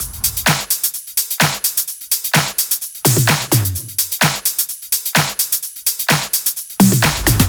VFH2 128BPM Unimatrix Kit 2.wav